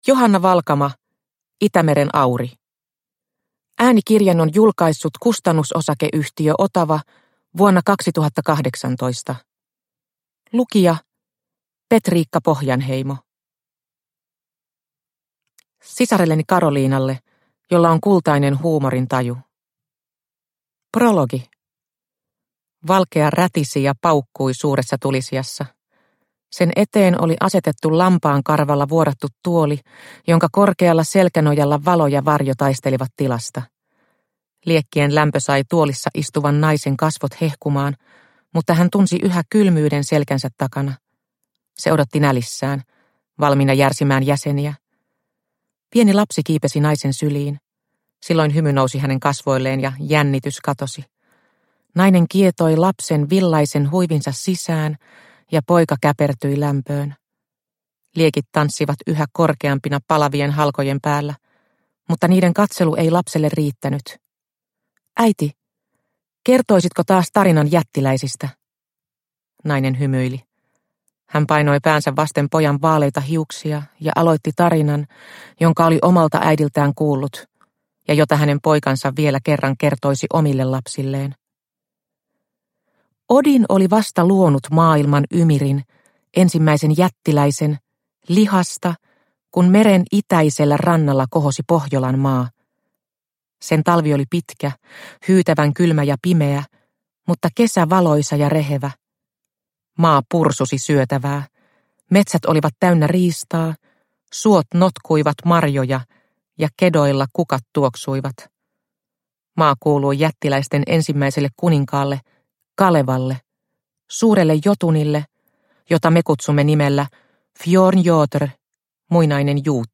Itämeren Auri – Ljudbok – Laddas ner